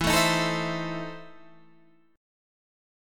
EM7sus4#5 chord